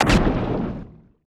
HAR SHOT 2.wav